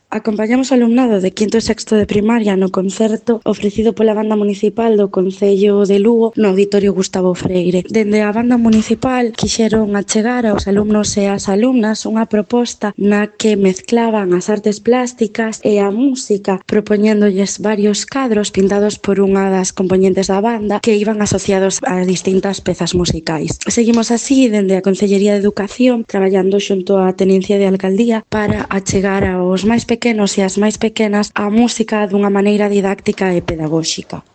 Audio La responsable de Educación y Difusión del Conocimiento, Iria Buide, sobre el Concierto Didáctico | Descargar mp3